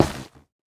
Minecraft Version Minecraft Version latest Latest Release | Latest Snapshot latest / assets / minecraft / sounds / block / nylium / step2.ogg Compare With Compare With Latest Release | Latest Snapshot